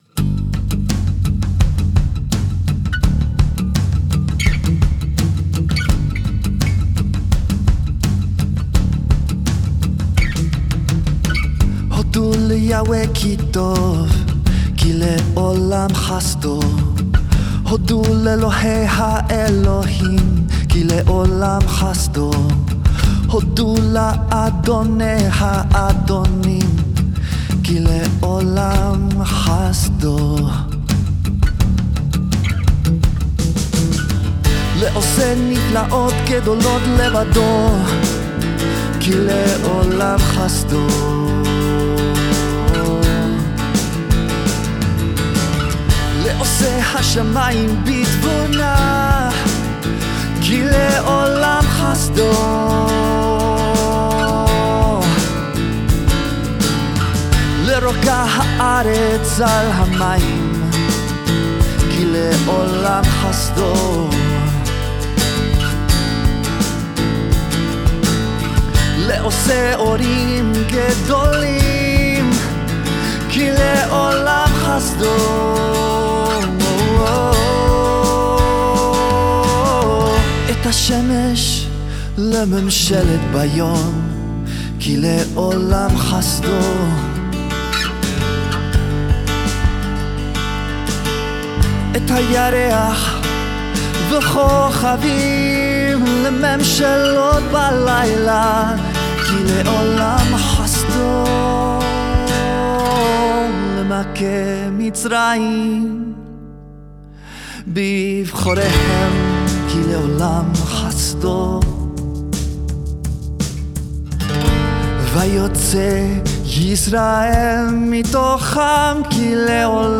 click on song / Chanting
Psalm-136 song.mp3